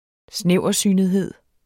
Udtale [ ˈsnεwˀʌˌsyˀnəðˌhəðˀ ]